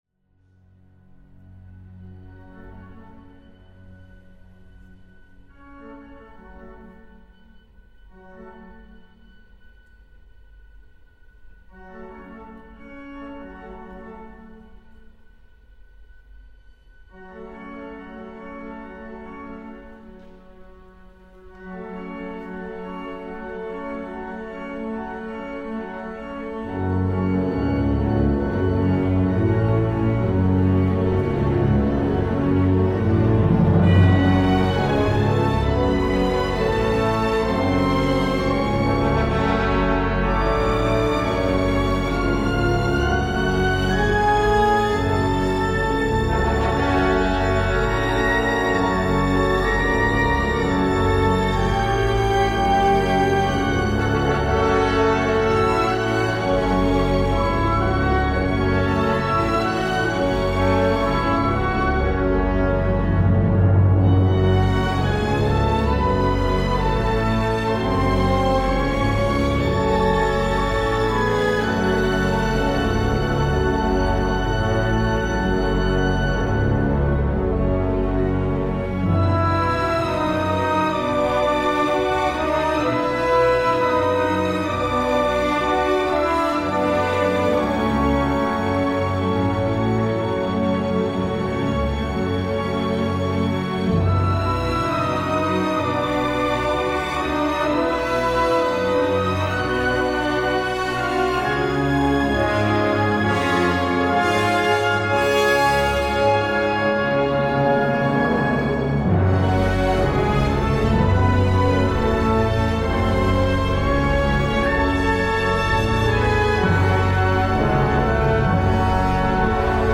sombre, vénéneux, romantique et grandiloquent !
Dantesque, viscéral et puissamment beau.